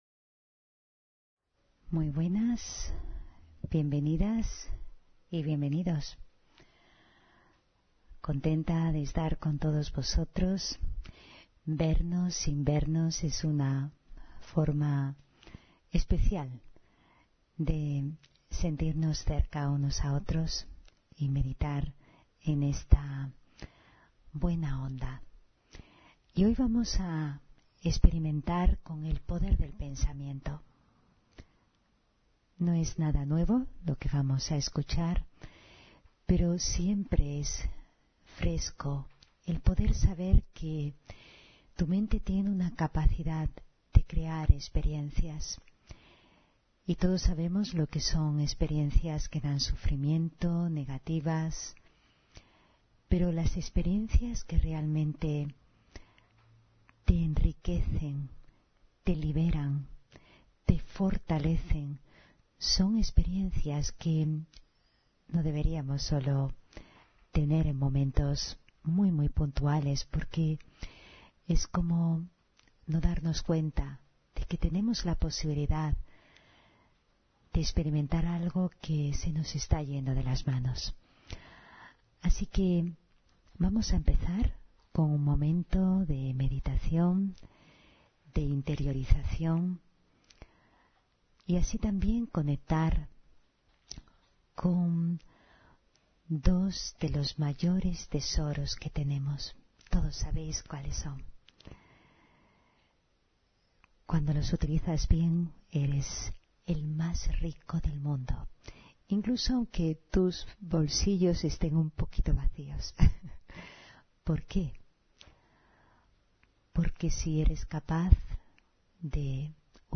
Meditación y conferencia: Experimenta con el poder del pensamiento (25 Septiembre 2024)